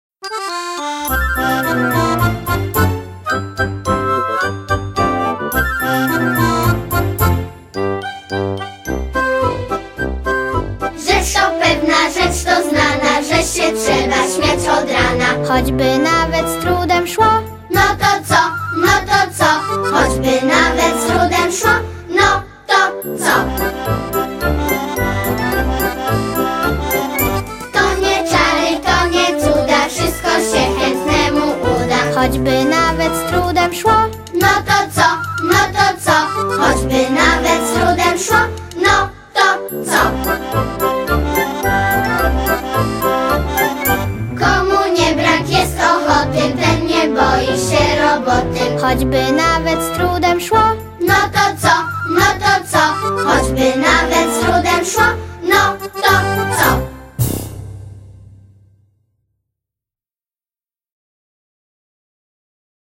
No_to_co_wersja_wok-instr
No_to_co_wersja_wok-instr.wma